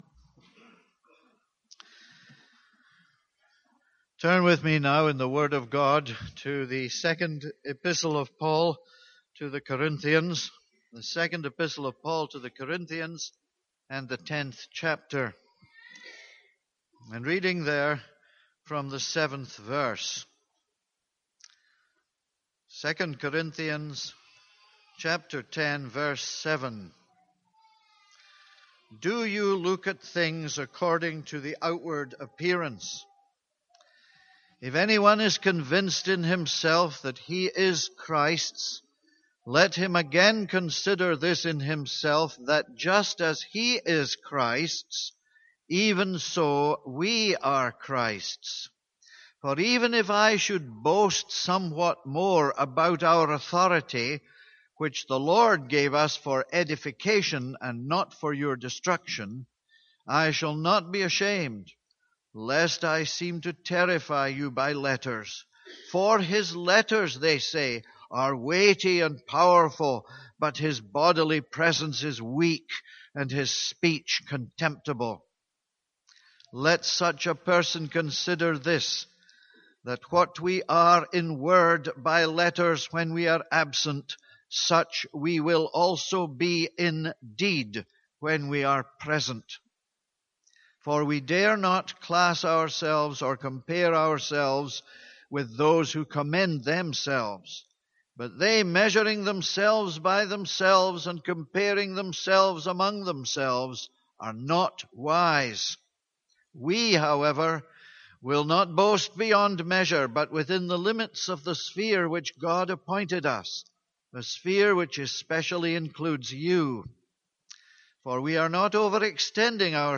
This is a sermon on 2 Corinthians 10:7-18.